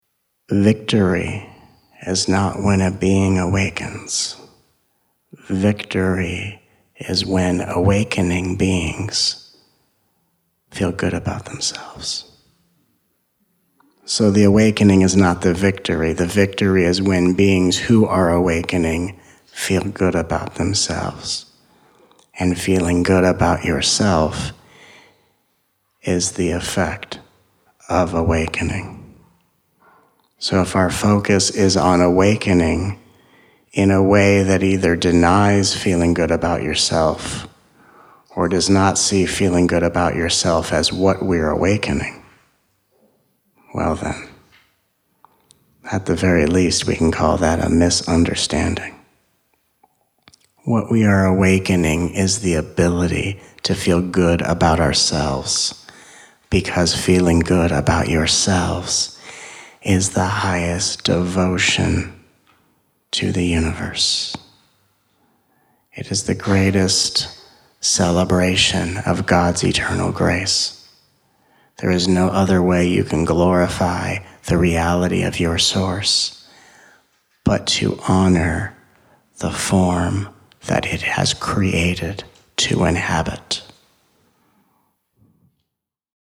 Experience the eternal peace, endless joy, and unwavering clarity that awakens all the way to the cellular level through these teachings from 3 different weekend retreats in 2015. 10 tracks with a total running time: 7 hours, 15 mins.
With each track encoded with the same transmission of presence offered at every single live event, you are invited to explore your absolute potential in the most direct, nurturing, and heart-centered way.